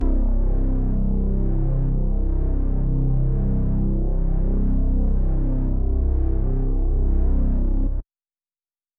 MOOG ONE SHOT BASS 3.wav